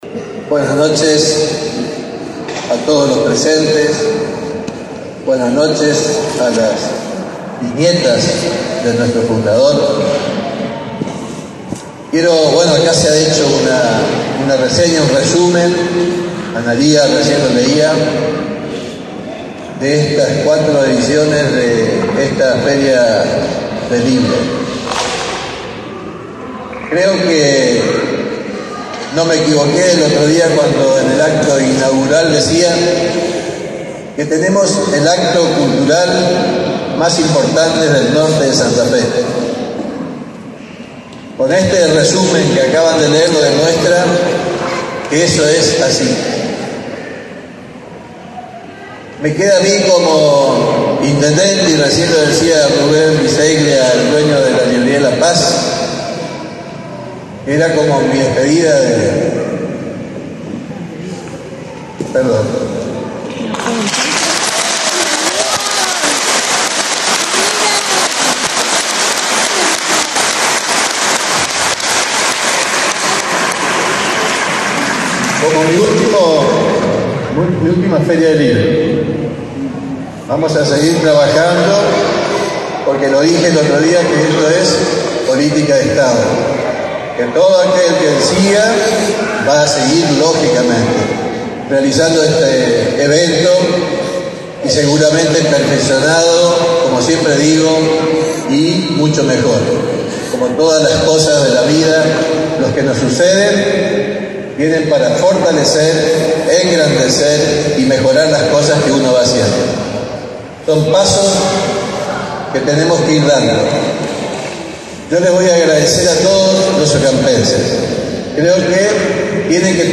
Villa Ocampo: EL DISCURSO DEL INTENDENTE ENRIQUE PADUAN EN EL CIERRE DE LA 4ª EDICIÓN DE LA FERIA DEL LIBRÓ JAAUKANIGAS. - Info Central :: Región Jaaukanigás
El acto de cierre desarrolló en el escenario principal de la feria.
Con entregas de reconocimientos y las emociones, el Intendente Enrique Paduan dejo su mensaje final.